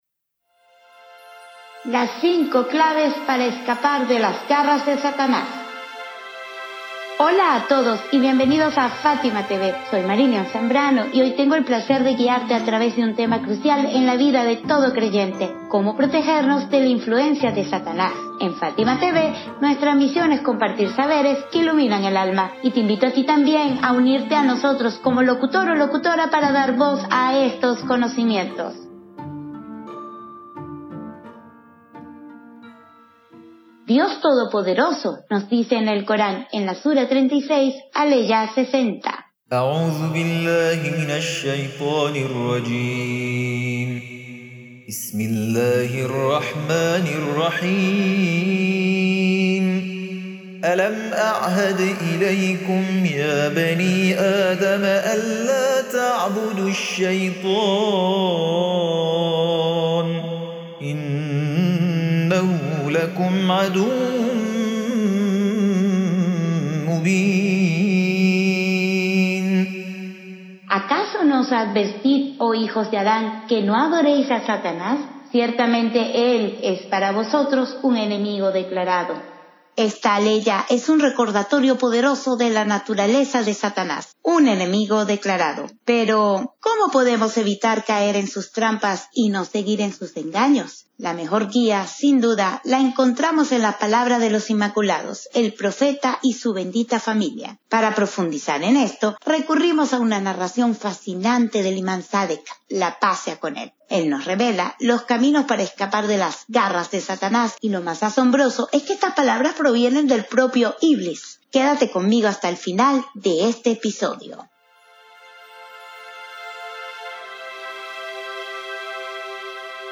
🎙 Locutora: